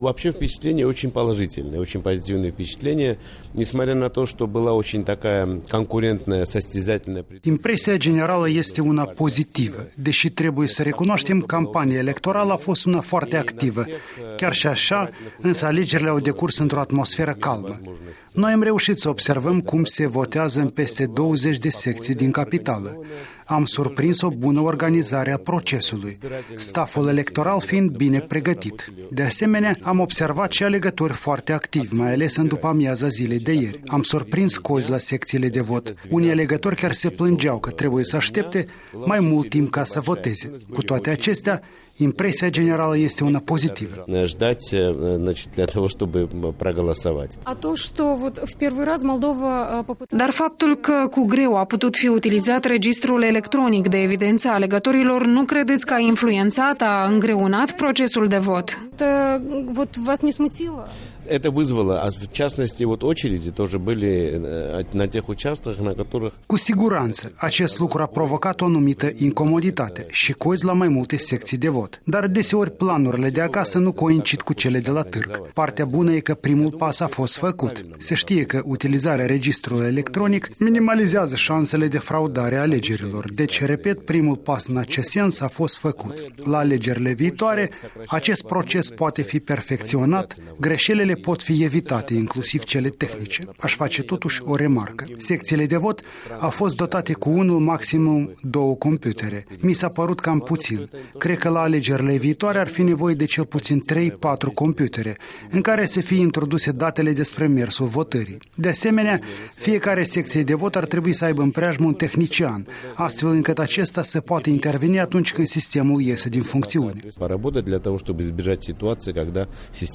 Interviu cu Valeri Chechelashvili